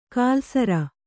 ♪ kālsara